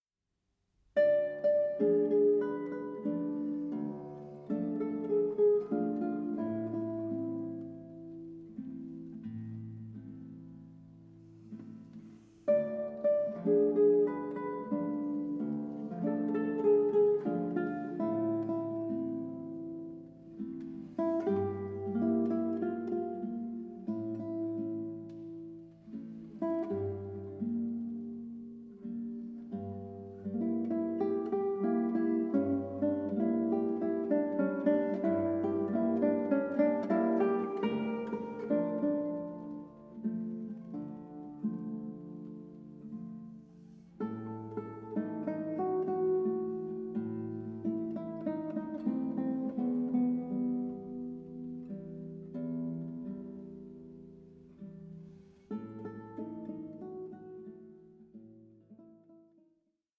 for Guitar